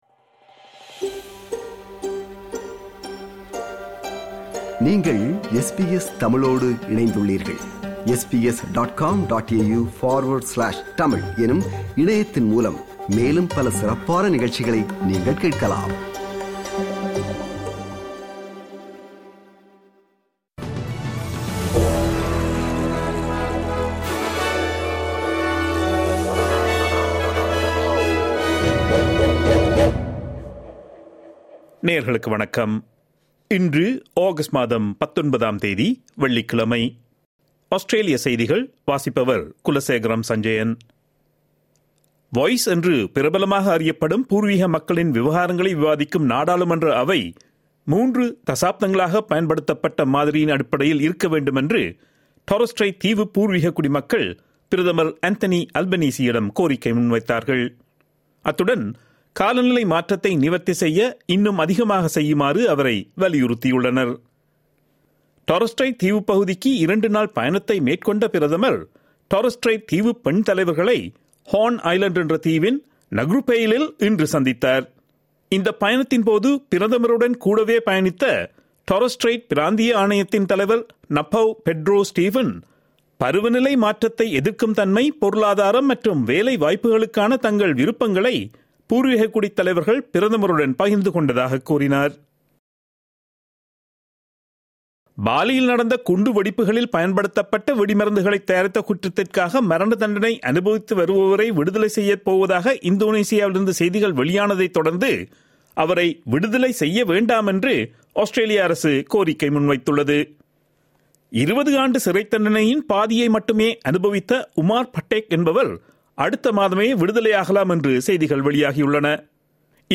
Australian news bulletin for Friday 05 August 2022.